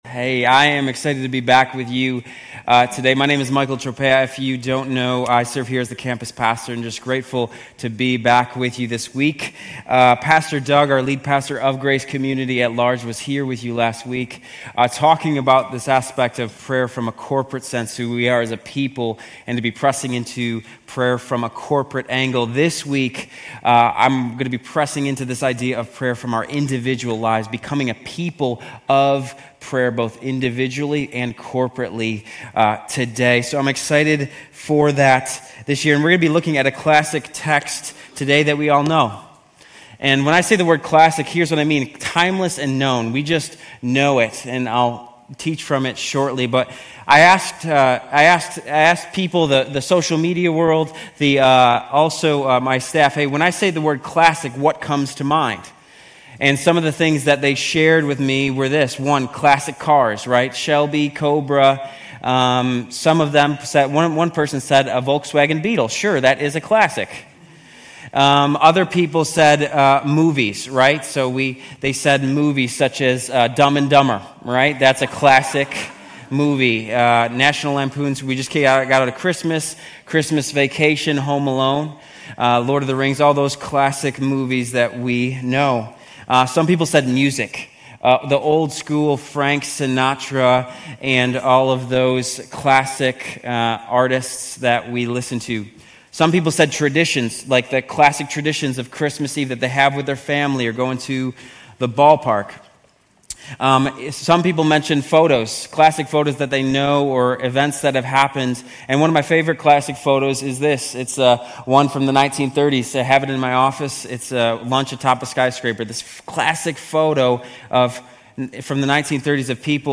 Grace Community Church University Blvd Campus Sermons Jan 12 - Prayer Jan 13 2025 | 00:34:26 1x 00:00 / 00:34:26 Subscribe Share RSS Feed Share Link Embed